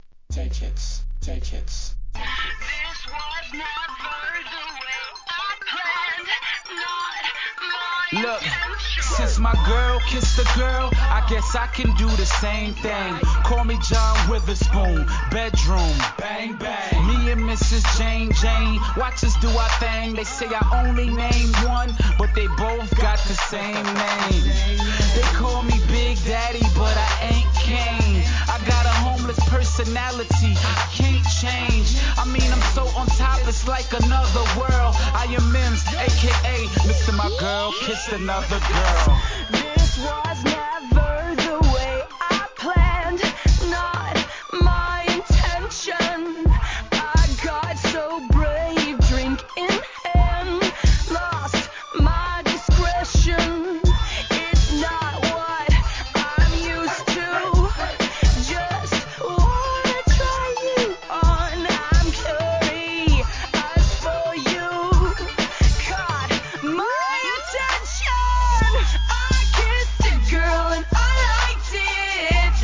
HIP HOP/R&B
社会現象を巻き起こした話題作のPOPナンバー!!